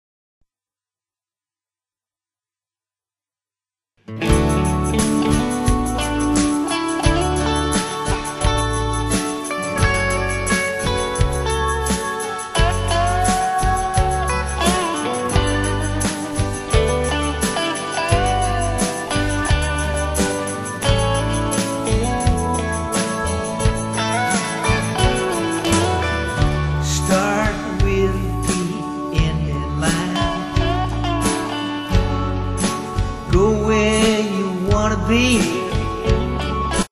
in a Country style